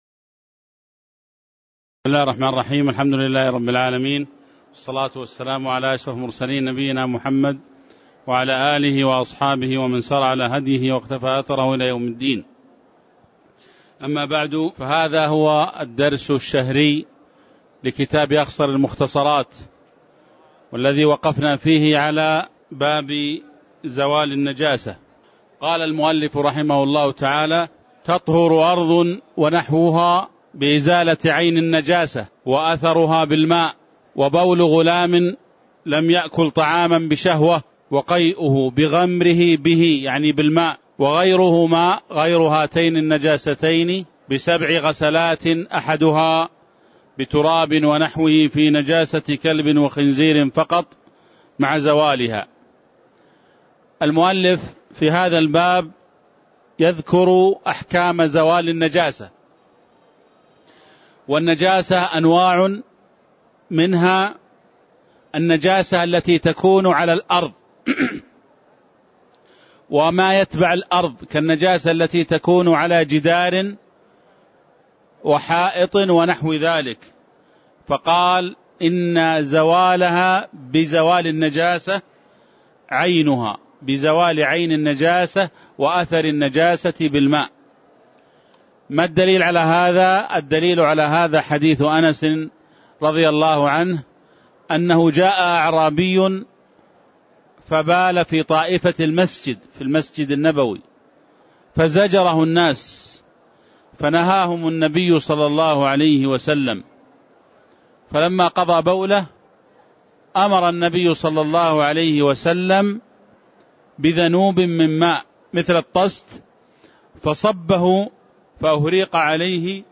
تاريخ النشر ٢٣ ربيع الأول ١٤٣٩ هـ المكان: المسجد النبوي الشيخ